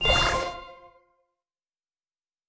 Special & Powerup (59).wav